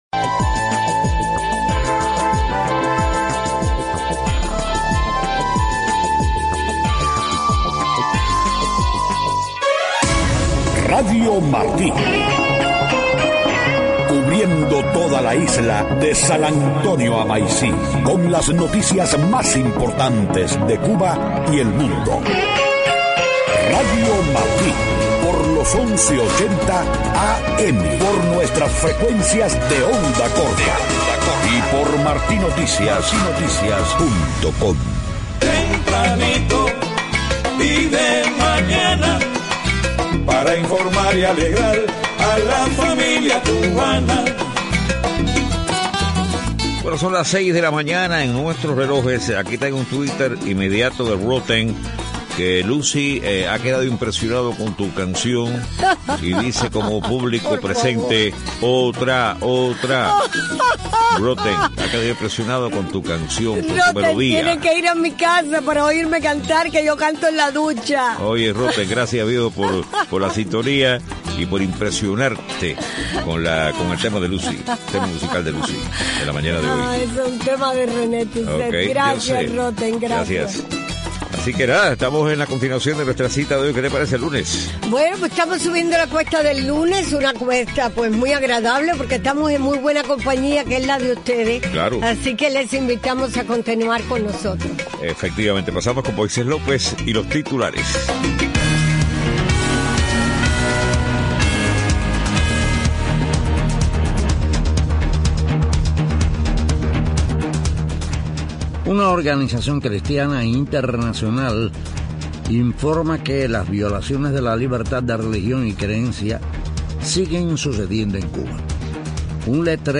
6:00 a.m. Noticias: Organización cristiana internacional informa que las violaciones de la libertad de religión y creencia siguen sucediendo en Cuba. Letrero en un edificio denuncia que hay miseria en un municipio de Guantánamo. Advierte que acciones de las FARC ponen en peligro el proceso de paz con el Gobierno de Colombia. Polonia negocia con Washington la instalación de armamento militar dentro de sus fronteras.